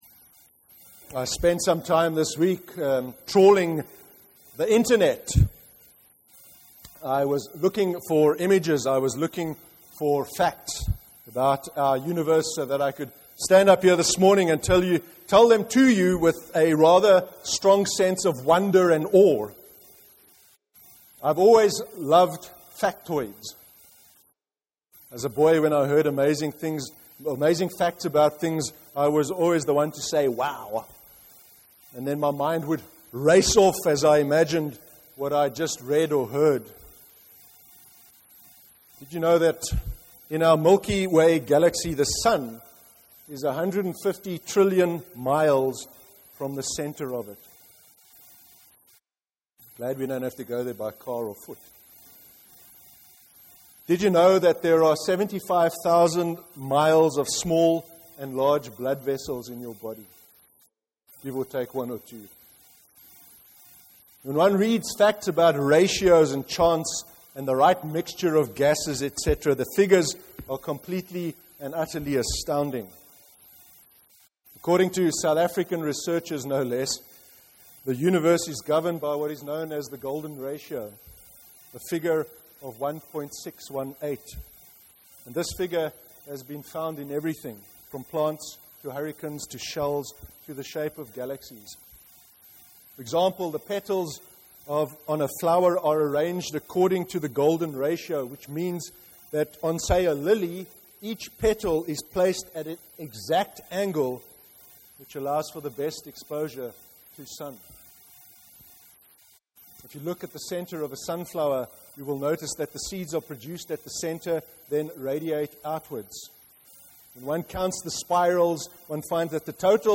03/04/2015 sermon: The wonders of God’s creation